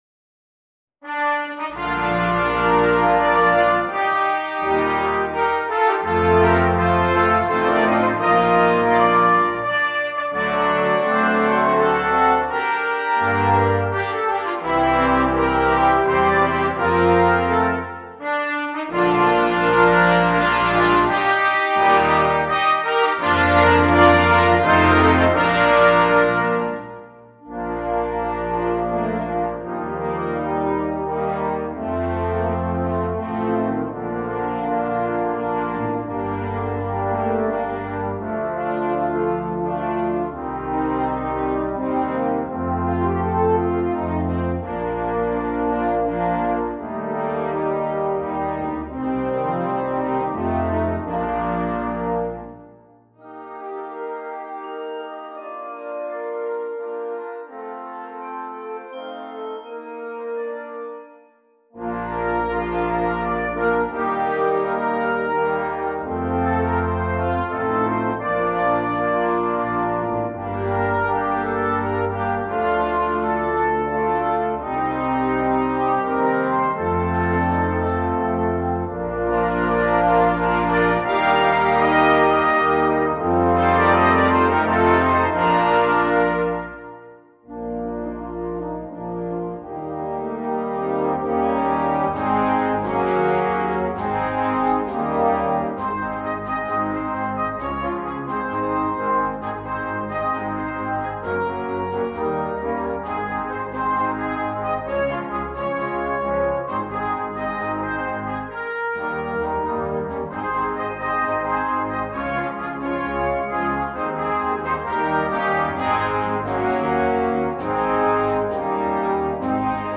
2. Brass Band
Full Band
without solo instrument
Entertainment